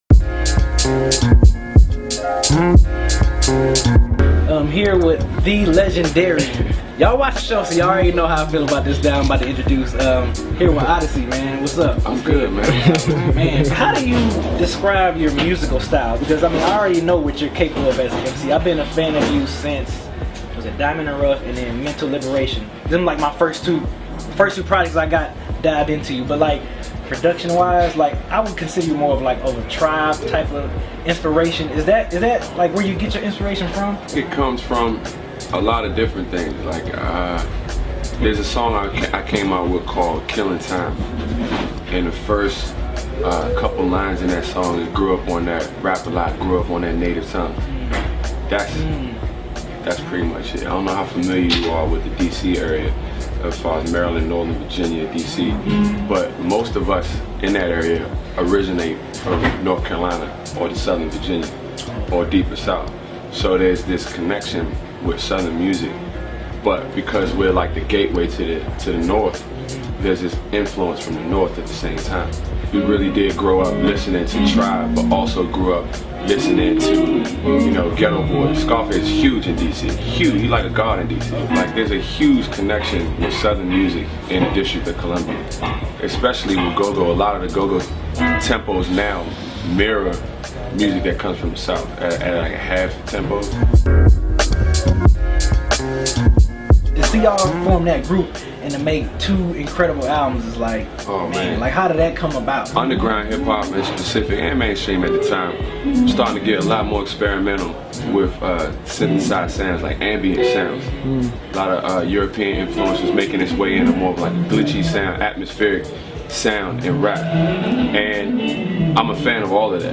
Oddisee Talks Music Streaming, N-Word/Profanity in Lyrics & More | DEHH Interview